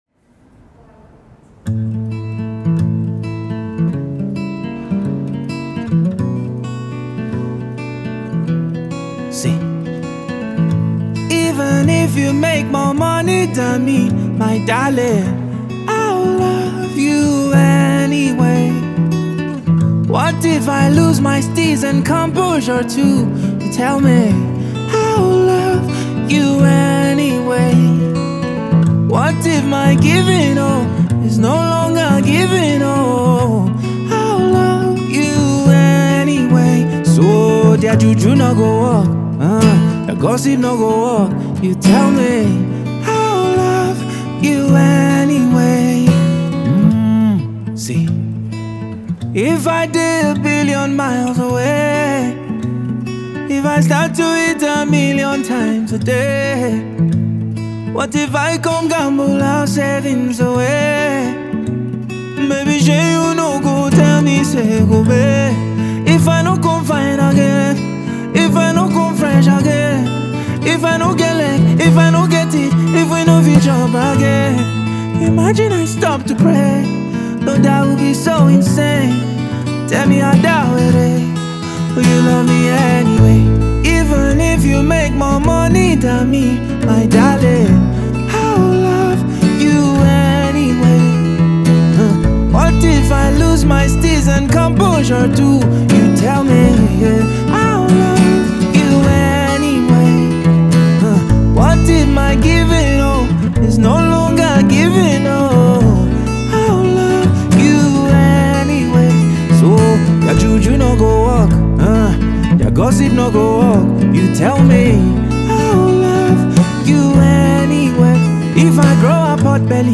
the acoustic version takes on a life of its own